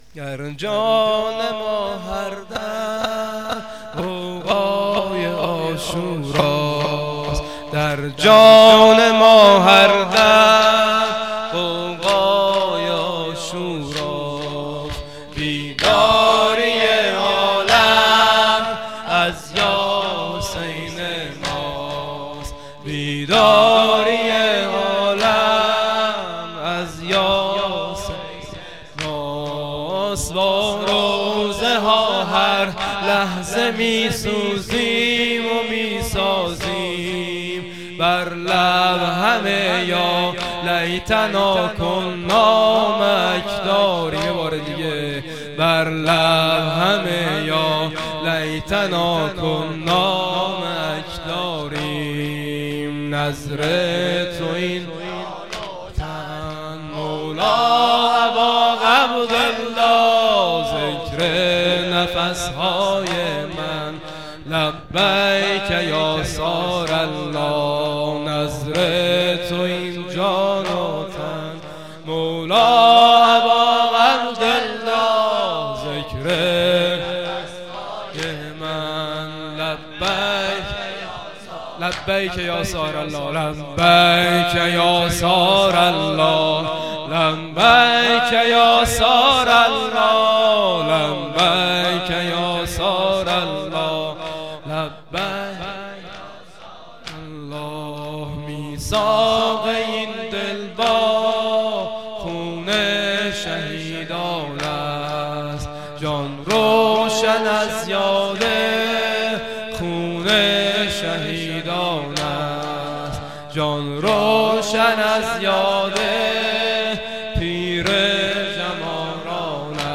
خیمه گاه - هیئت قتیل العبرات - سرود همگانی